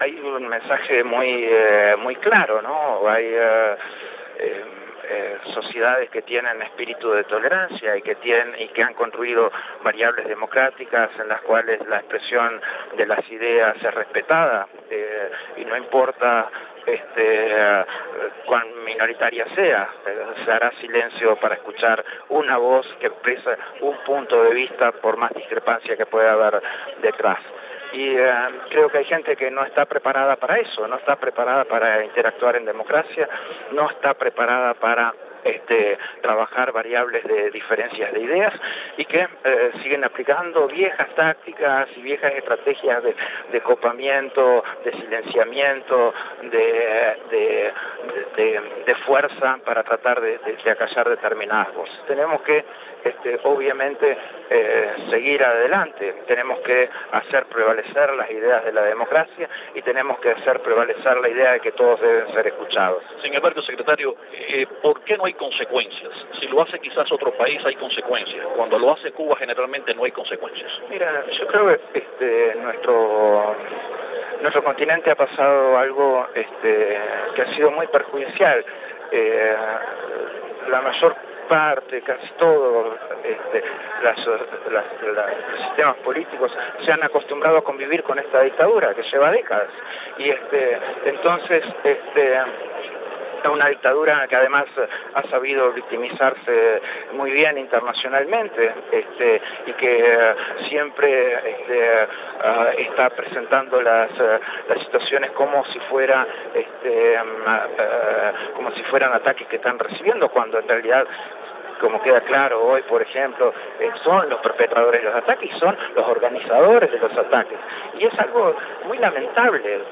Declaraciones desde Lima del secretario general de la OEA, Luis Almagro